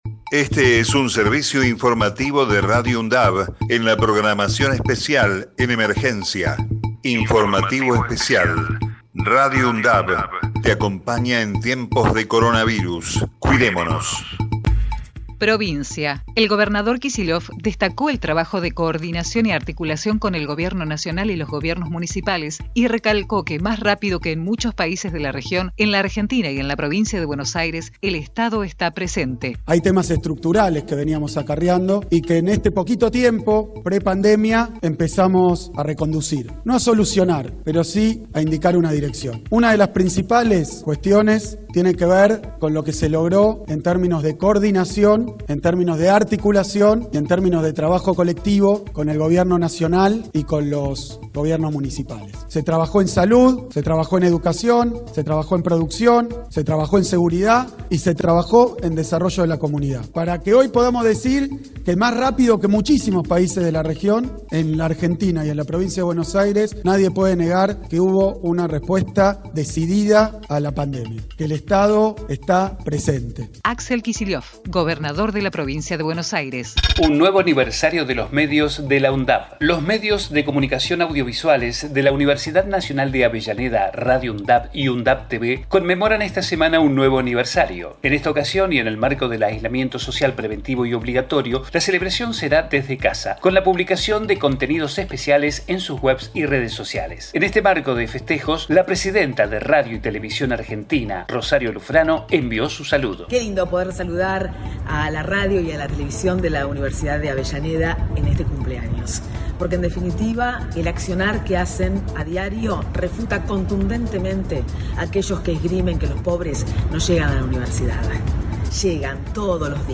COVID-19 Informativo en emergencia 05 de mayo 2020 Texto de la nota: Este es un servicio informativo de Radio UNDAV en la programación especial en emergencia.